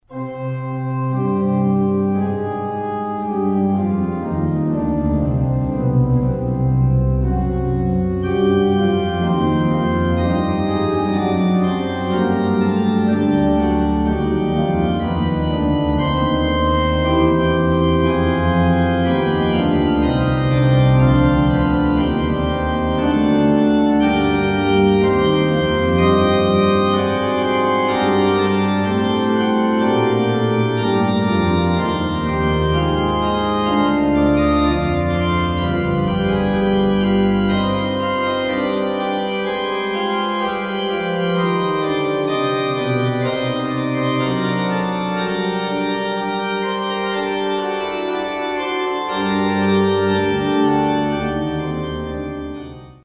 Československá dlouhohrající deska 1950